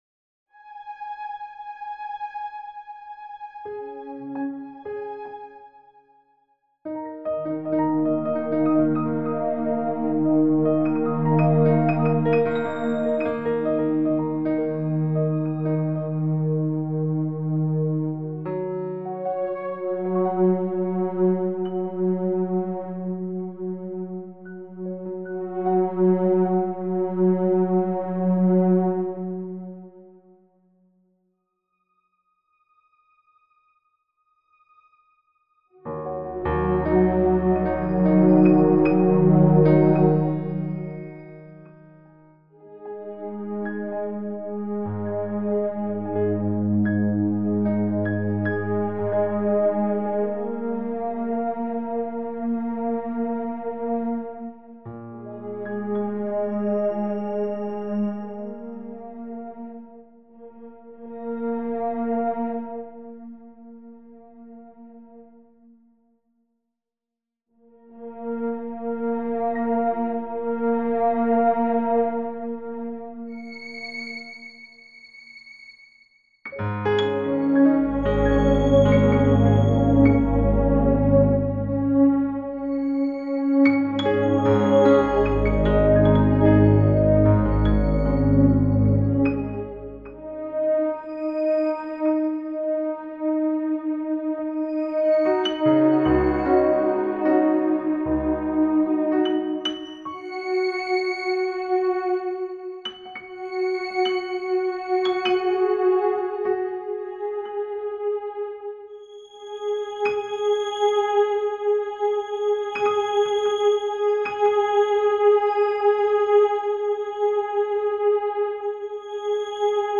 Sound Art
This sound art piece interpreted bio-electrical recordings of the growth of living lingzhi mushrooms. After electrodes collect data from lingzhi mycelium, the bio-electrical recordings are saturated by the amplifier and turned into music with algorithm.
Electrical signals in plants respond to environmental stimulations, hence through sound, audiences can experience the way patterns and melodies change in relationship to fluctuations in the environment where the lingzhi sculpture were cultivated.